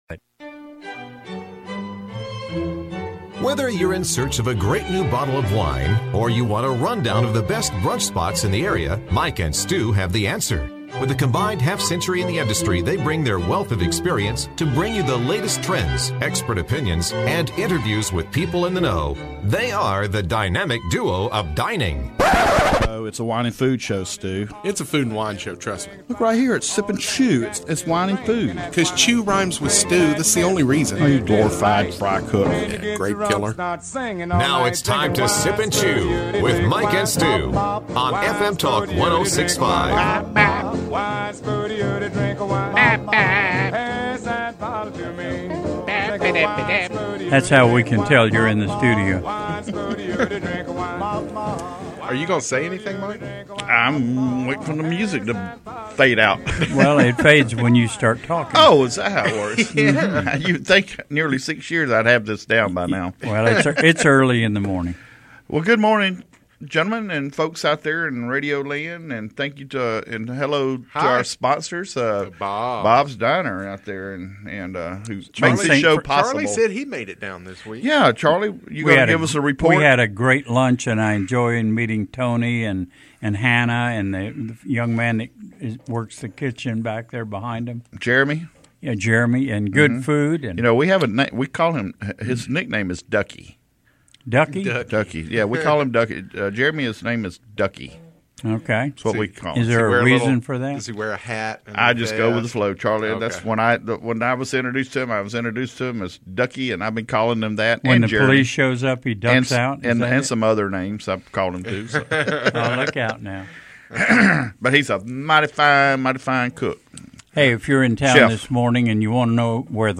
interviews from the National Food Championships.